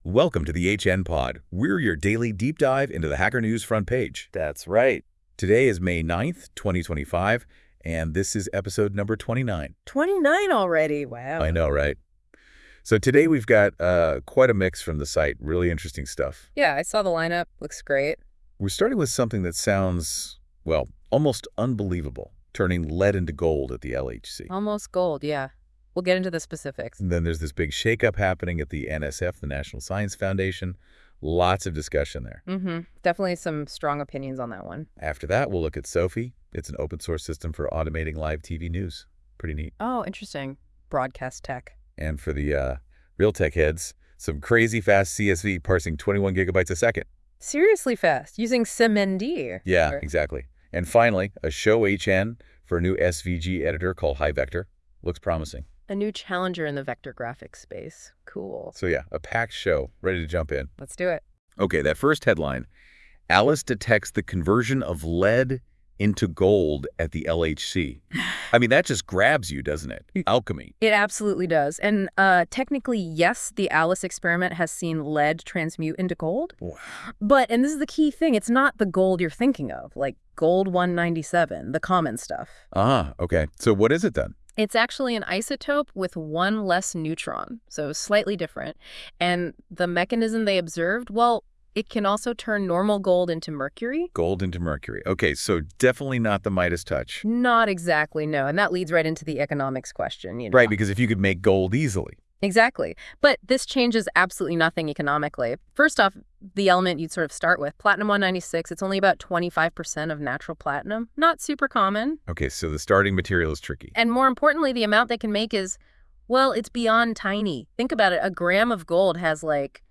This episode is generated by 🤖 AI.